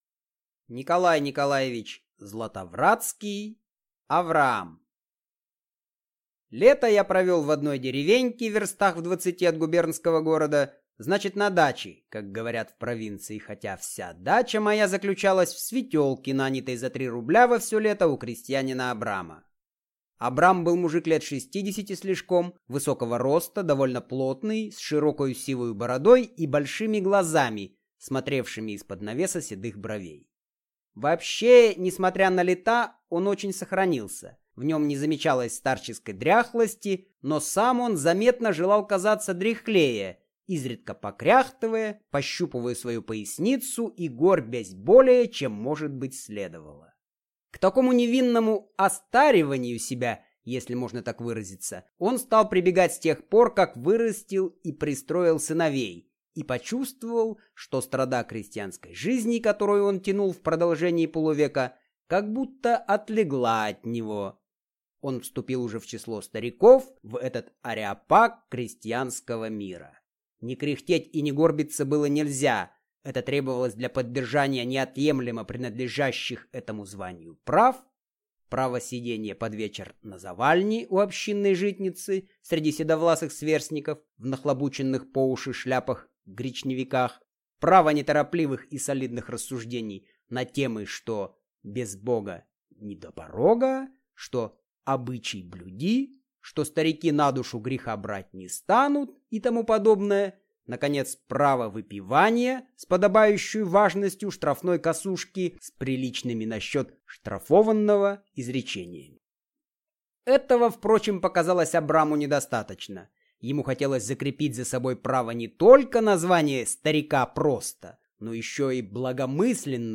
Аудиокнига Авраам | Библиотека аудиокниг